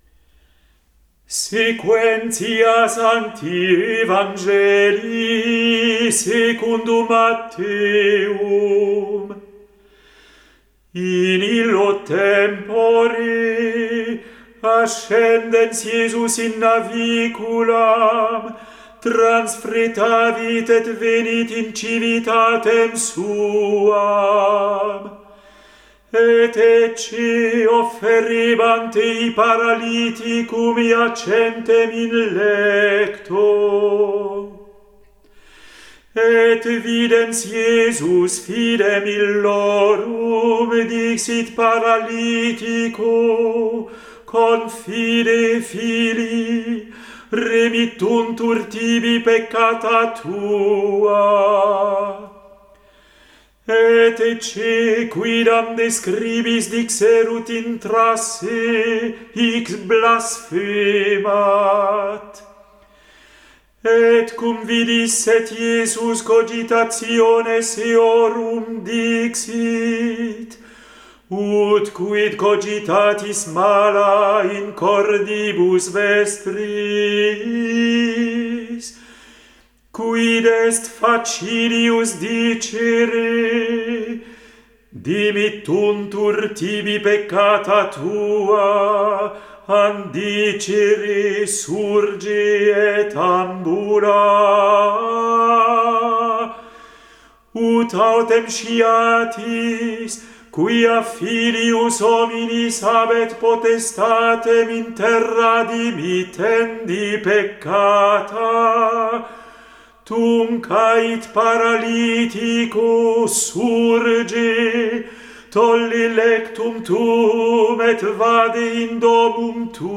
Evangelium